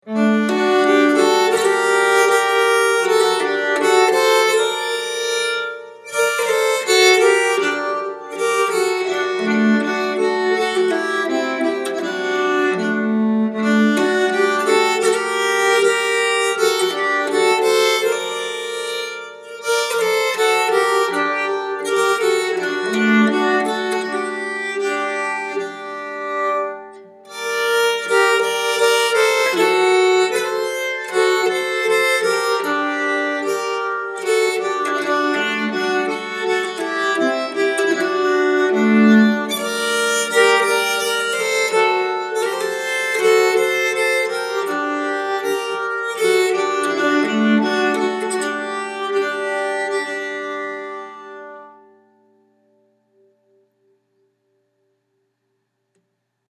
Méthode de nyckelharpa : Les Livrets
brudmarsch 11.mp3